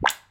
clap3.mp3